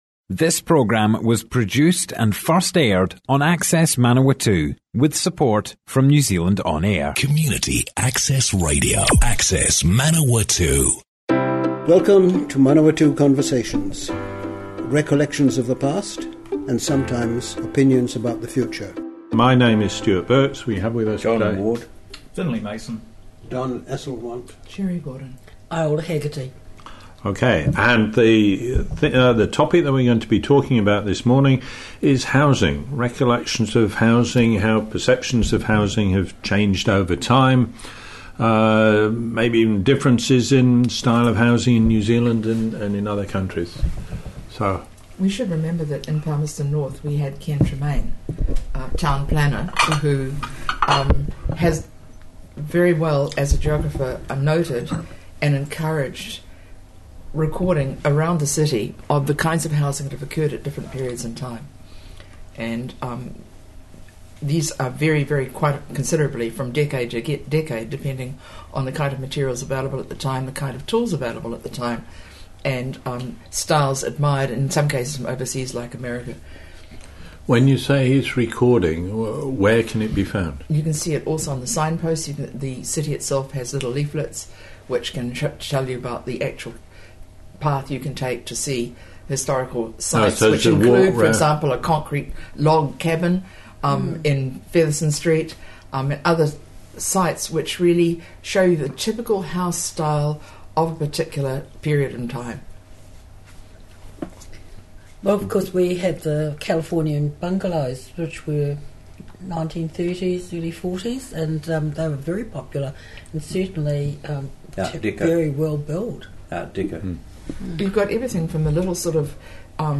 00:00 of 00:00 Add to a set Other Sets Description Comments Housing styles - Manawatu Conversations More Info → Description Broadcast on Access Manawatu on 29 May 2018, a group discussion of building styles and materials at various times in New Zealand. Topics include: ceiling heights, heating, ventilation, food safes, design errors, and threats (floods, earthquakes, liquefaction).